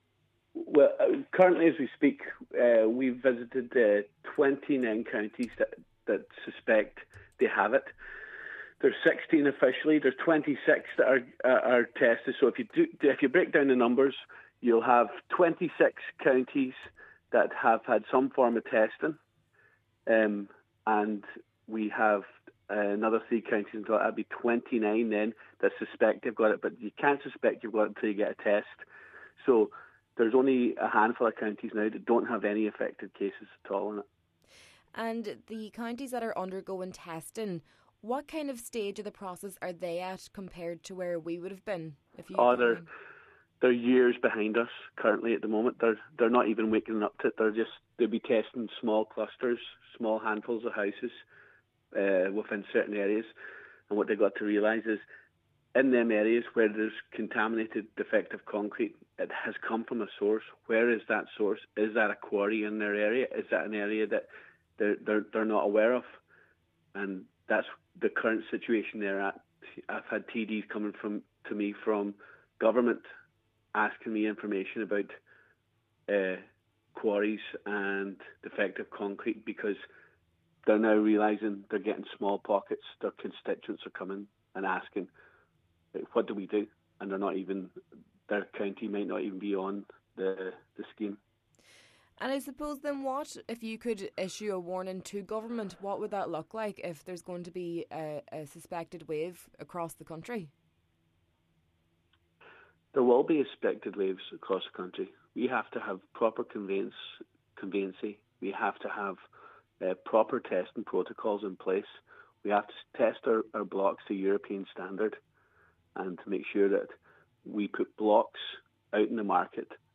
Speaking to Highland Radio News following a meeting with Derry and Strabane District Council, Deputy Ward said that 26 counites have undergone testing and many are finding themselves in the position Donegal was in several years ago.